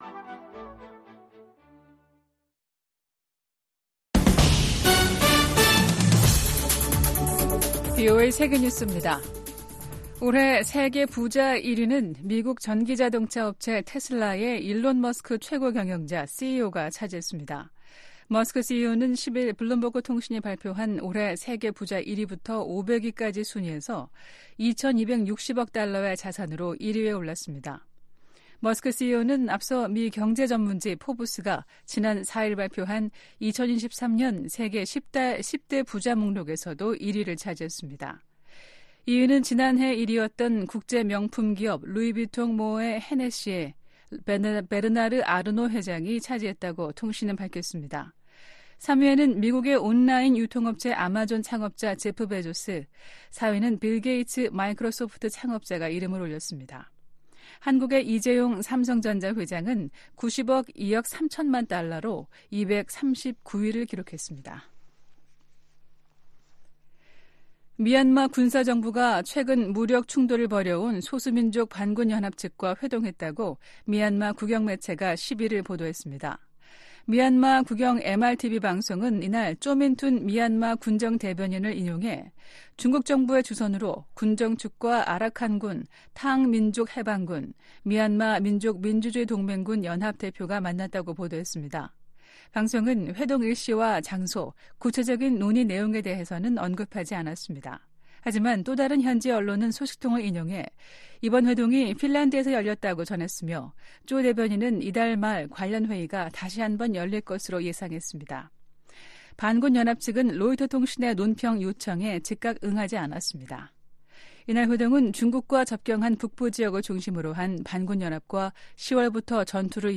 VOA 한국어 아침 뉴스 프로그램 '워싱턴 뉴스 광장' 2023년 12월 12일 방송입니다. 미국과 한국, 일본이 새 대북 이니셔티브를 출범하면서 북한 정권의 핵과 미사일 기술 고도화의 자금줄 차단 등 공조를 한층 강화하겠다고 밝혔습니다. 미 상하원의원들이 중국 시진핑 정부의 탈북민 강제 북송을 비판하며, 중국의 인권이사국 자격 정지 등 유엔이 강력한 대응을 촉구했습니다. 영국 의회가 북한의 불법 무기 개발과 인권 문제 등을 다룰 예정입니다.